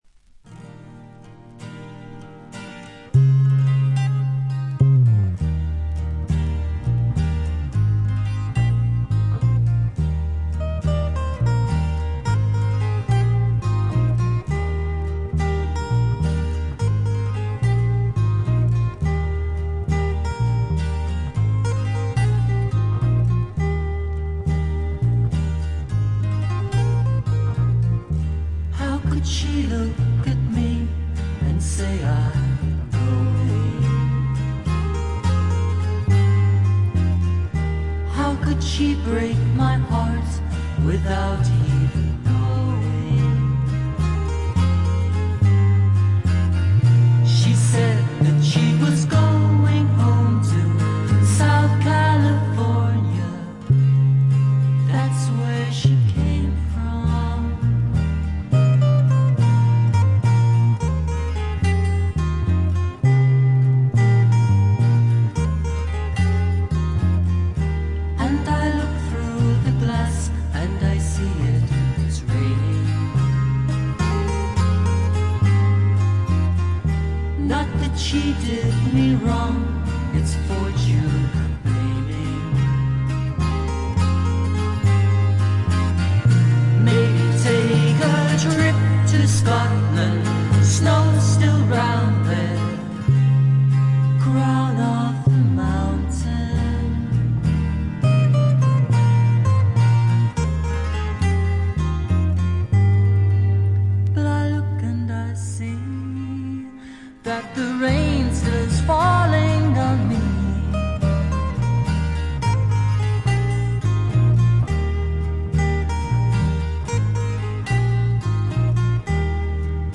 軽微なプツ音少々、静音部でわずかなチリプチ。
ギター、マンドリン、ダルシマー等のアコースティック楽器のみによるフォーク作品。
試聴曲は現品からの取り込み音源です。